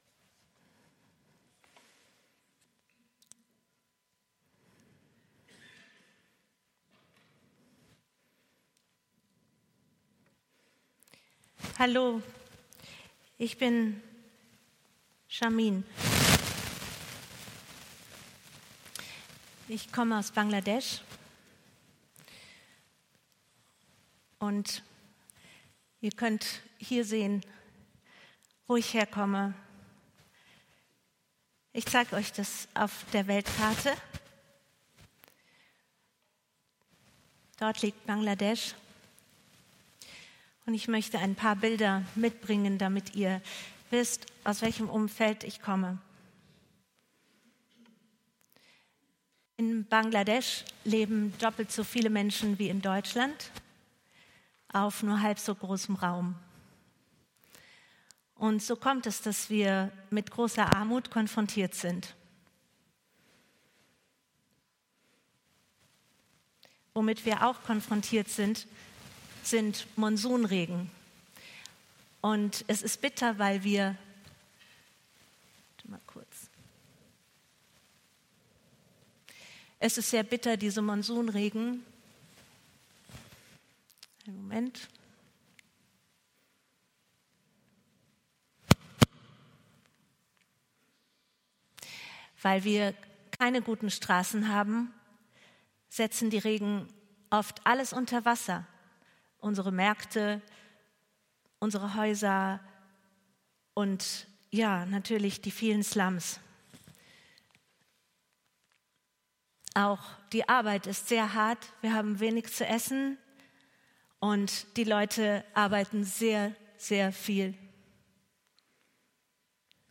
Predigt vom 03.03.2024